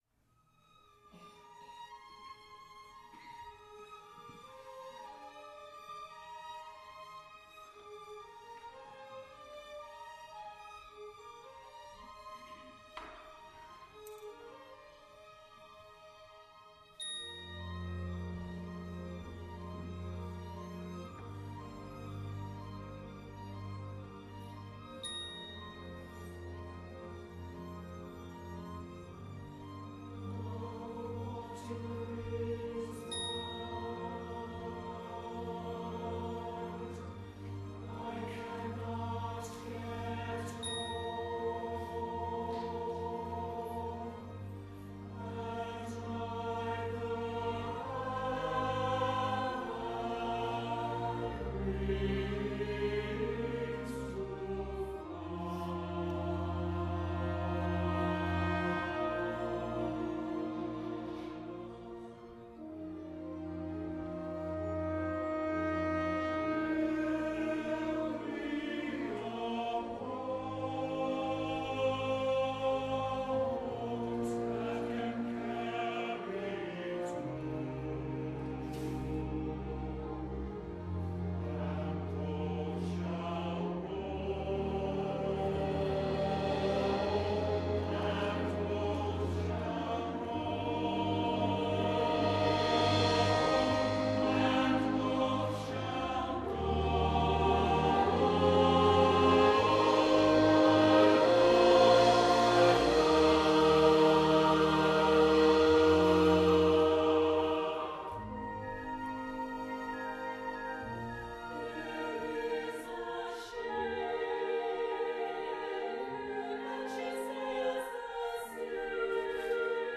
for SATB Chorus and Chamber Orchestra (1996)